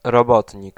Ääntäminen
Synonyymit salarié diligent Ääntäminen France: IPA: [tʁa.va.jœʁ] Haettu sana löytyi näillä lähdekielillä: ranska Käännös Ääninäyte 1. robotnik {m} 2. robotnica {f} Suku: m .